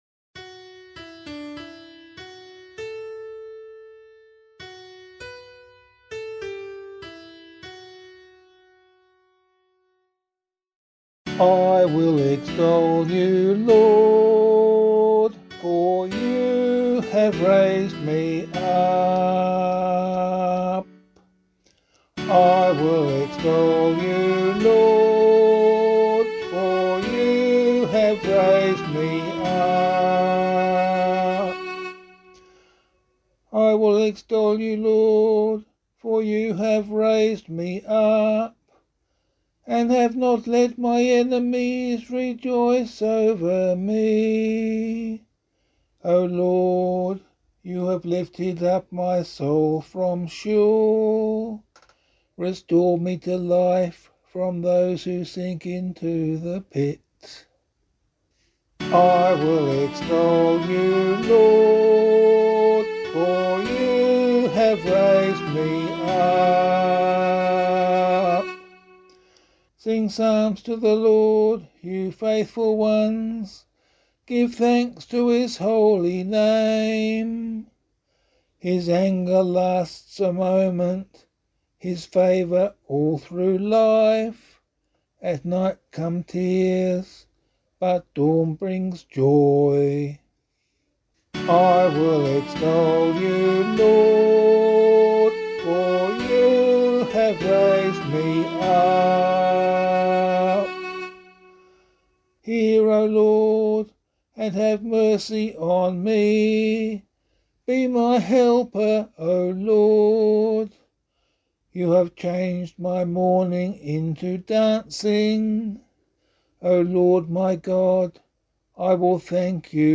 025 Easter 3 Psalm C [APC - LiturgyShare + Meinrad 5] - vocal.mp3